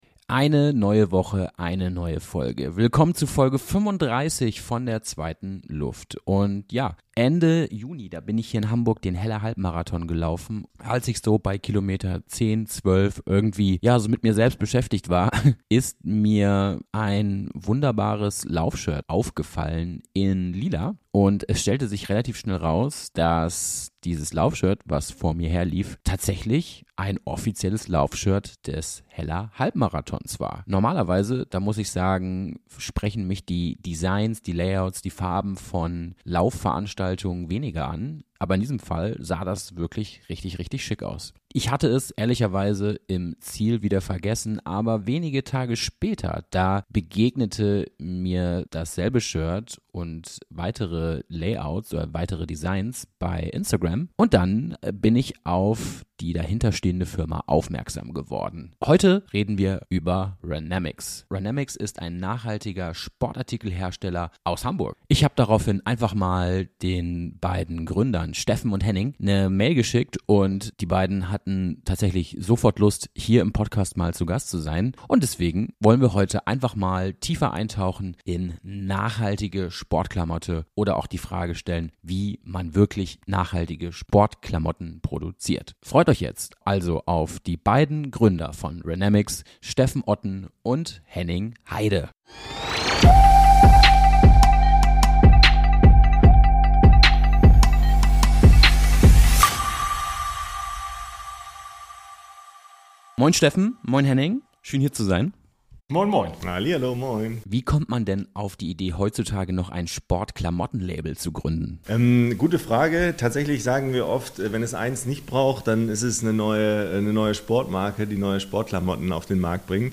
Sorry für das Rauschen